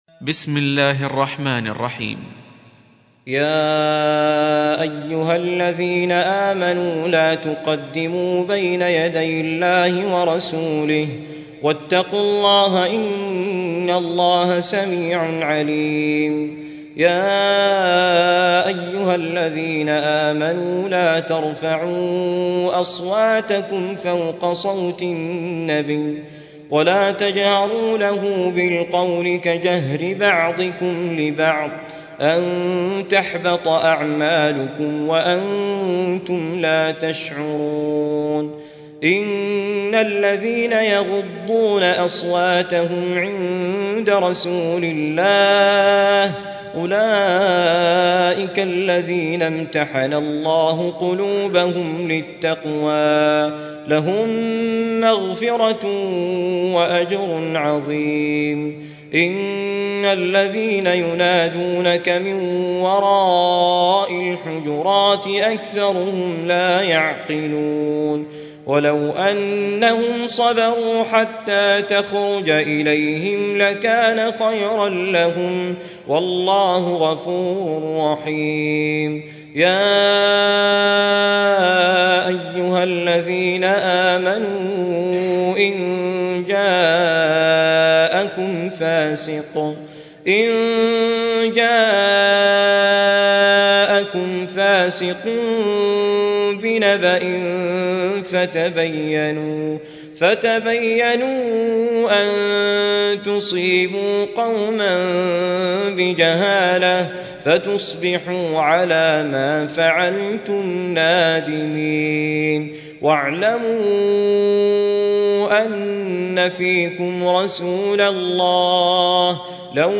Surah Repeating تكرار السورة Download Surah حمّل السورة Reciting Murattalah Audio for 49. Surah Al-Hujur�t سورة الحجرات N.B *Surah Includes Al-Basmalah Reciters Sequents تتابع التلاوات Reciters Repeats تكرار التلاوات